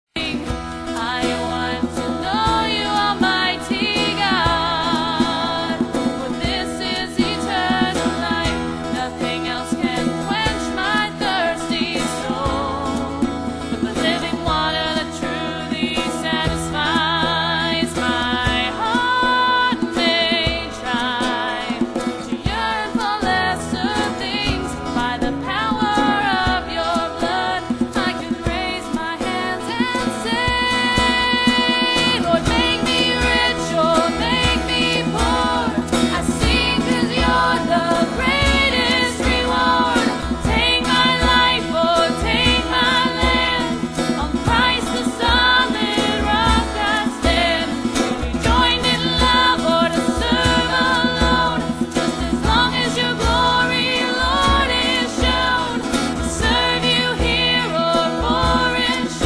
It's very amateur, so don't expect a Grammy-worthy performance.
Guitar
Percussion